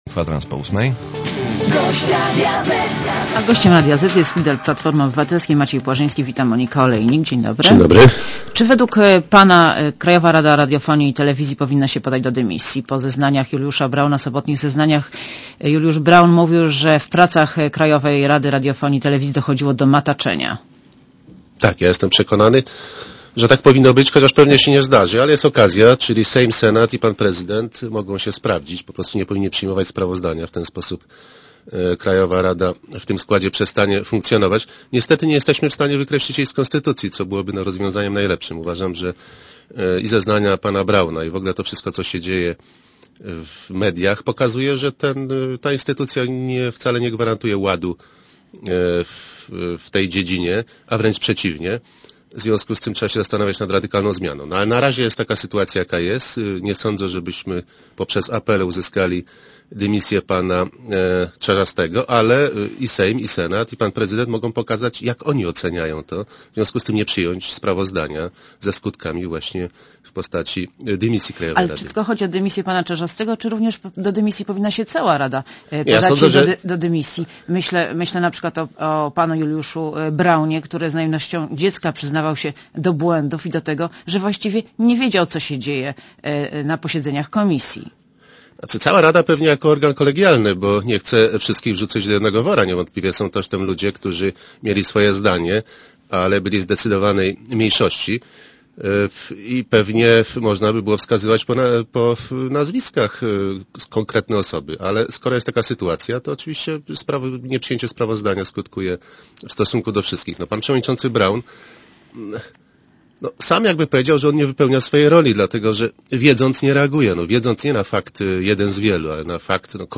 Monika Olejnik rozmawia z Maciejem Płażyńskim, liderem Platformy Obywatelskiej